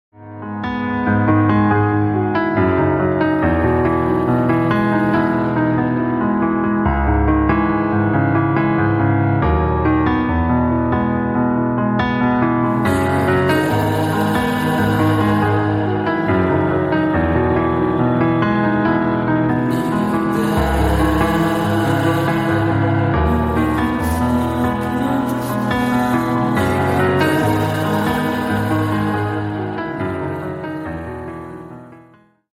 Спокойные И Тихие Рингтоны » # Рингтоны Альтернатива
Рок Металл Рингтоны